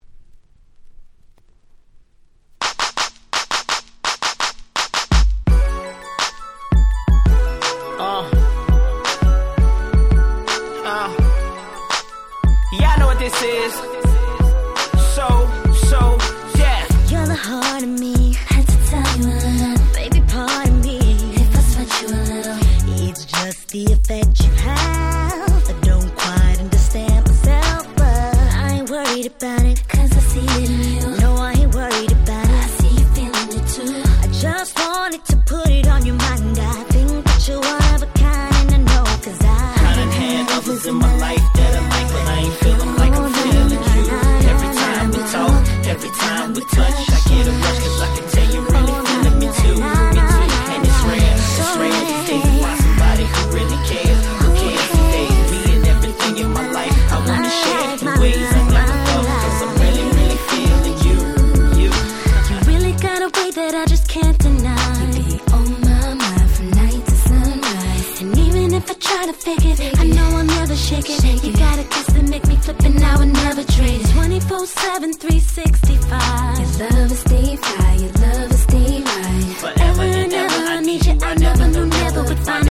06' Smash Hit R&B !!
MellowでSexyなSmash Hit !!
00's キャッチー系